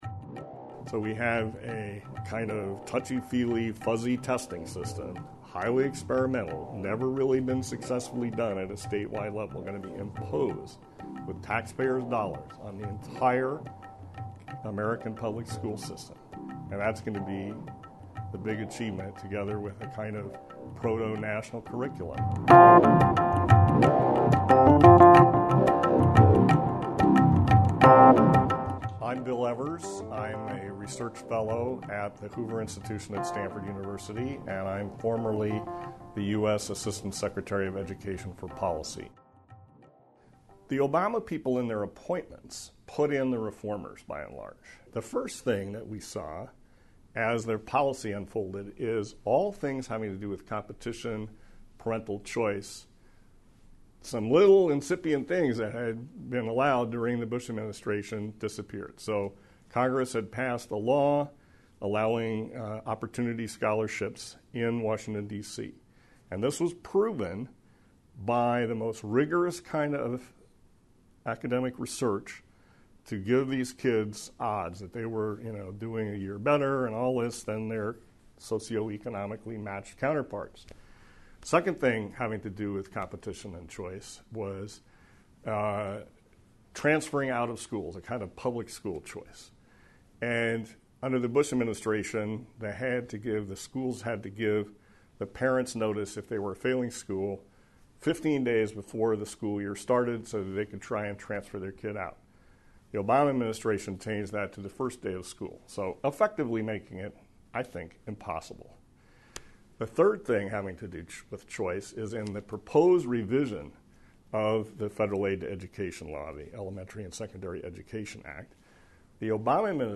Education Under Obama: A conversation with former assistant secretary of education Bill Evers